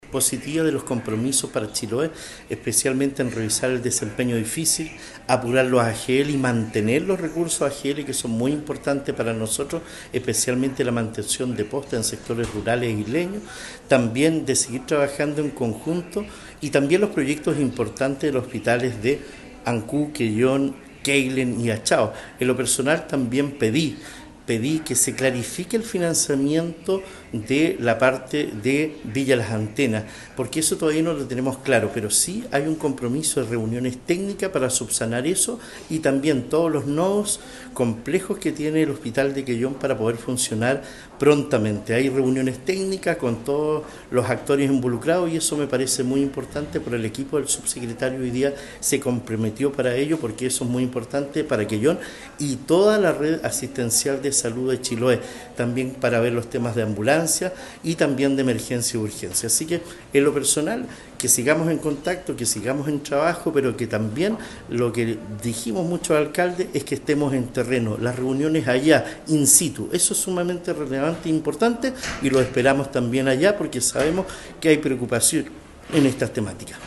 Además de otros programas a lo que se suma la necesidad de abordar la puesta en marcha de los nuevos hospitales que se están construyendo, aspectos que presentó en lo local el alcalde de Quellón Cristian Ojeda.